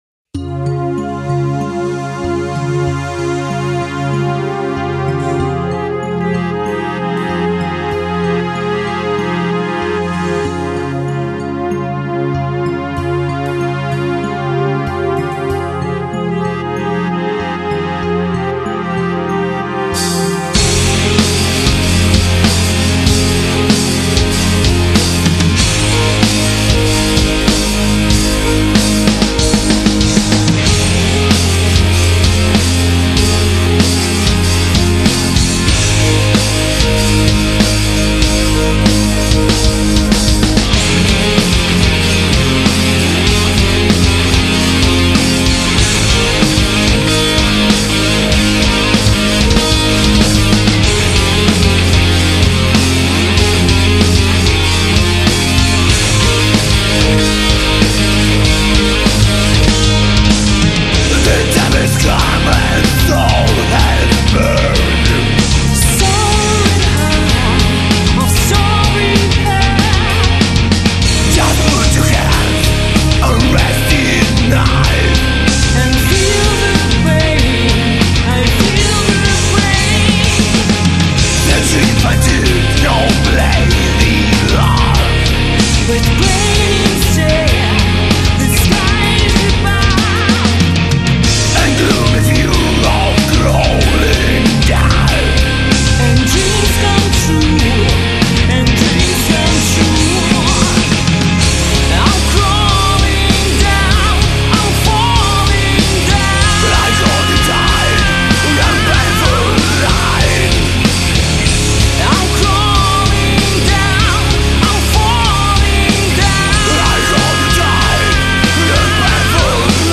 Guitar
Bass
Vocals